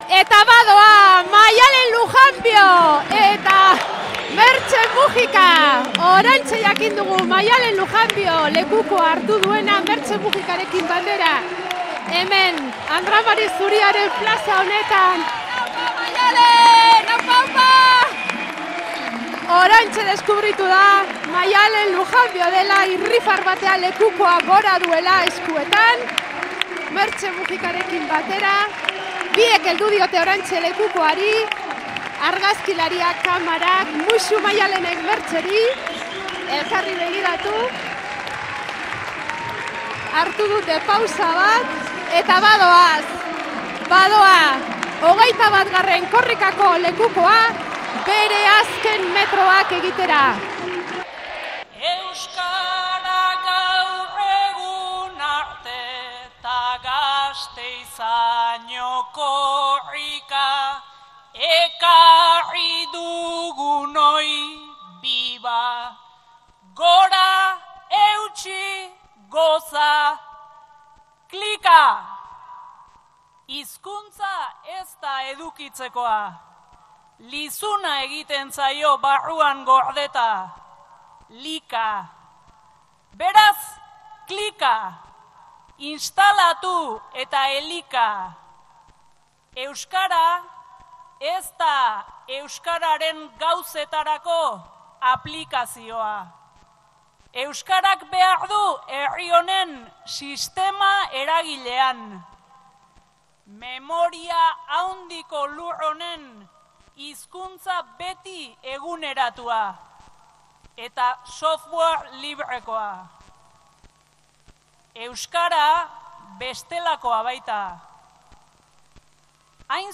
Maialen Lujanbiok irakurri du Korrikako mezua